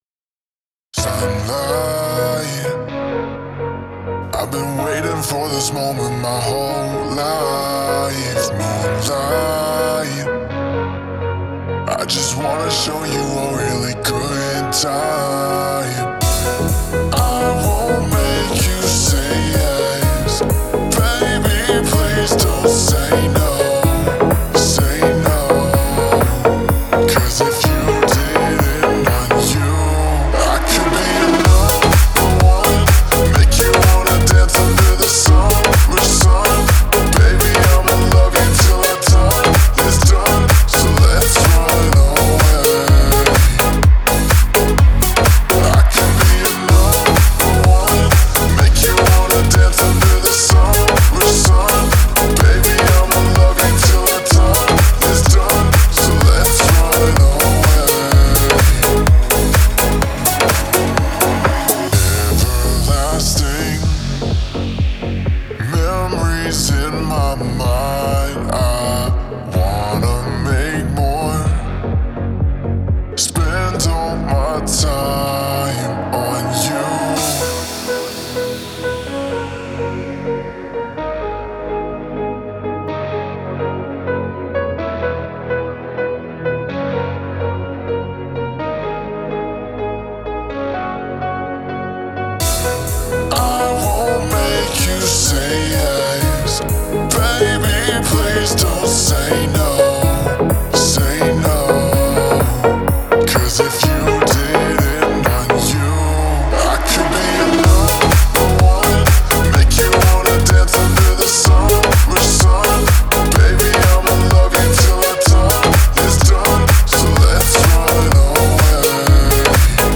это зажигательная поп-песня